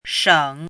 “省”读音
shěng
国际音标：ʂəŋ˨˩˦;/ɕiŋ˨˩˦
shěng.mp3